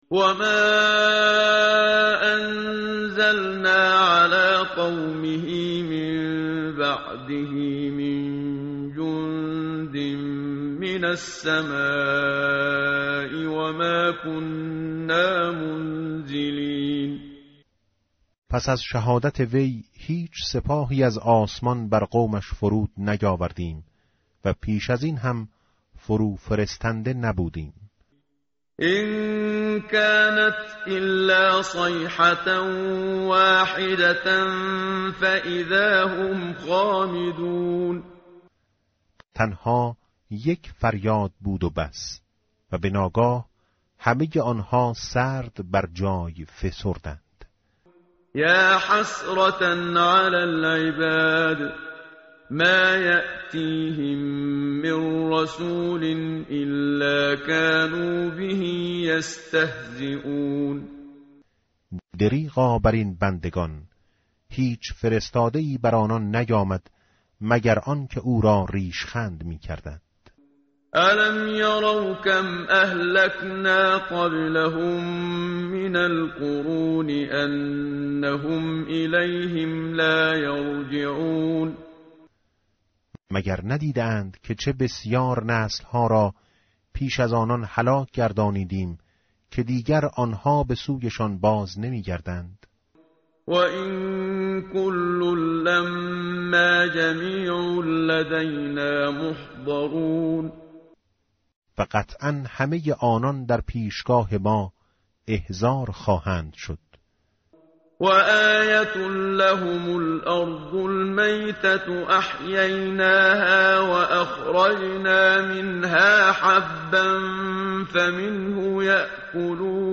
متن قرآن همراه باتلاوت قرآن و ترجمه
tartil_menshavi va tarjome_Page_442.mp3